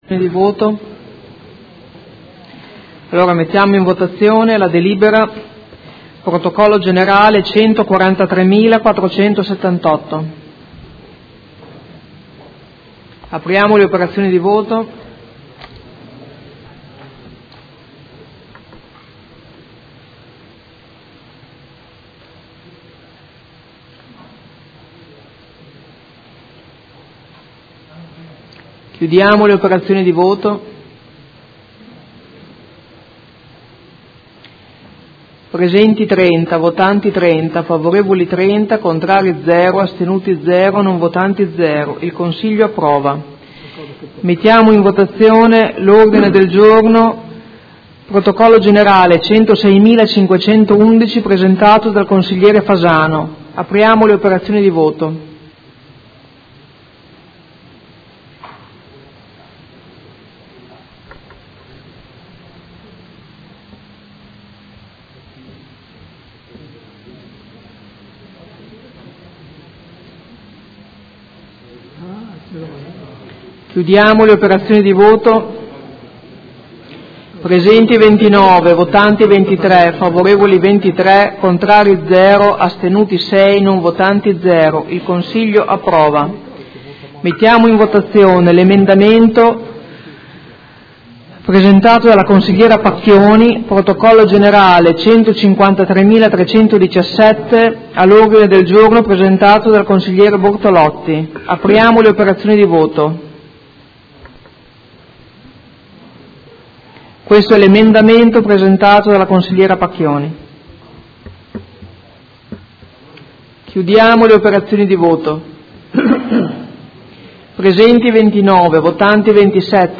Seduta del 20/10/2016. Mette ai voti proposta di deliberazione e ordini del giorno sul tema della solidarietà civica